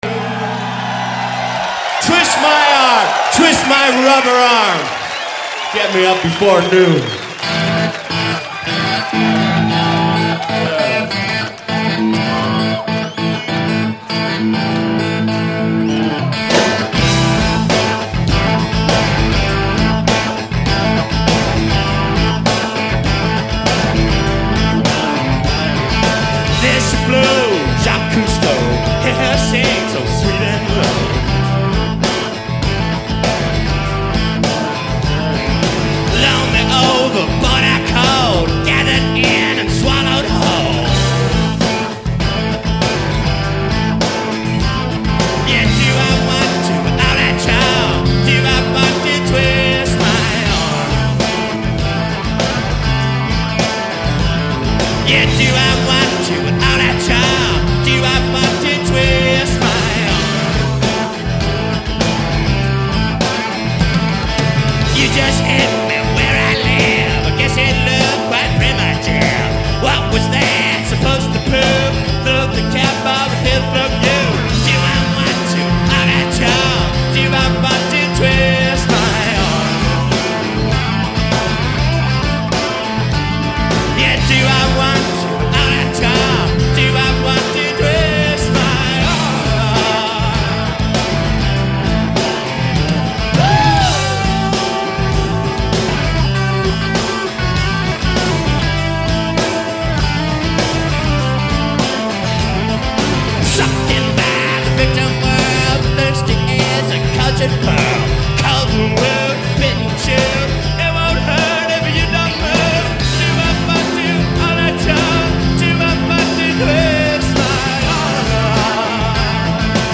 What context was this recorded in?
Source: Radio